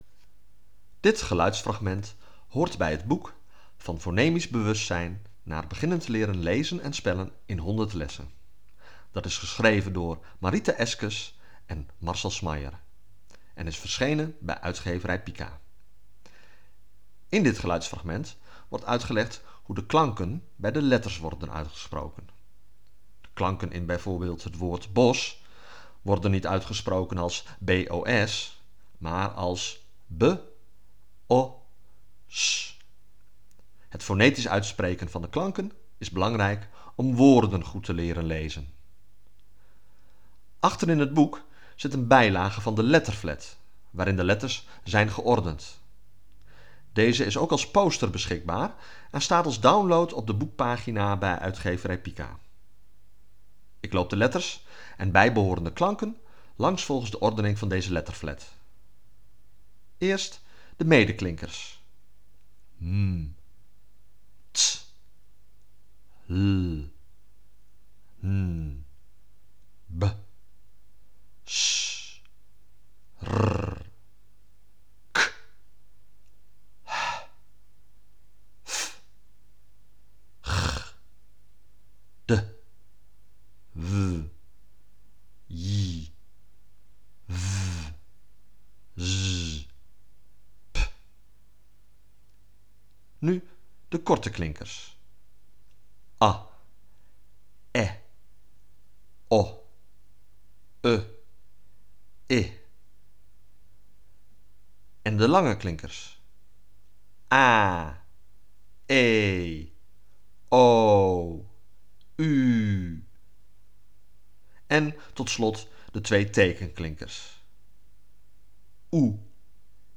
Audiofragment uitspraak klanken bij de letters in de letterflat
Audiofragment-uitspraak-klanken-bij-de-letters-in-de-letterflat.wav